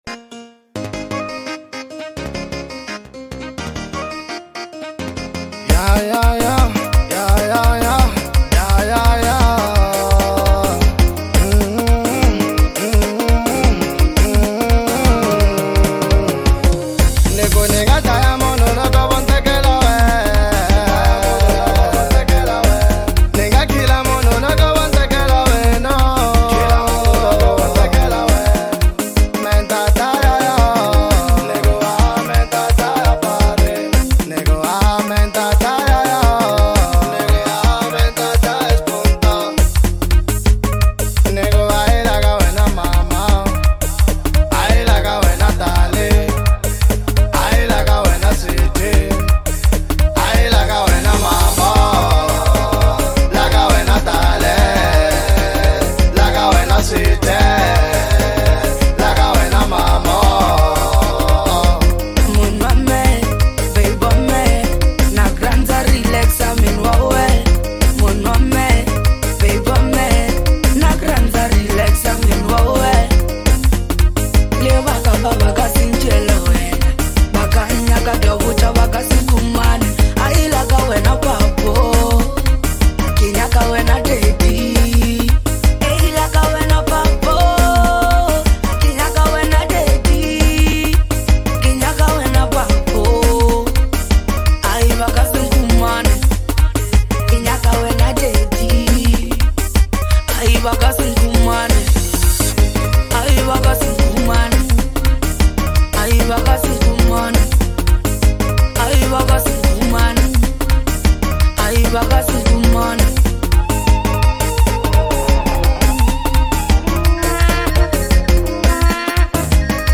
A Powerful Anthem of Truth and Expression